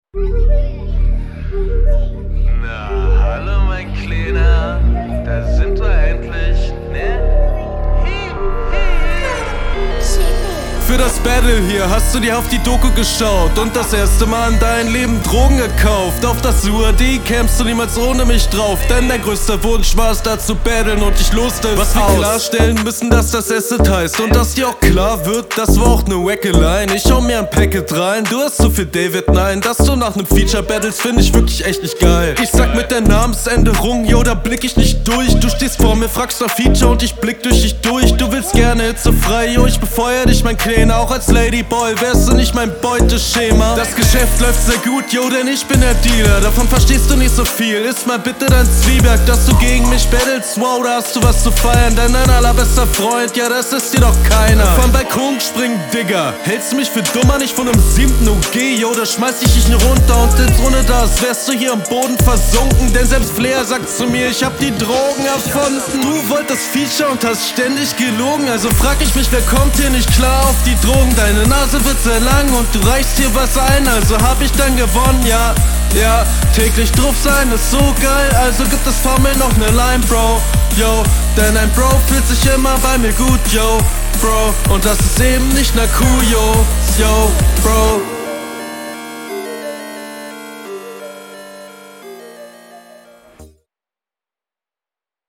Auch gut gereimt, der Flow ist in Ordnung aber an ein paar Stellen nicht ganz …
Flowlich gefällt mir hier die Stimme besser, die Flows sind nur nicht so linealgenau wie …
Insgesamt rappst du gar nicht schlecht, aber der Flow ist insgesamt wackliger als in der …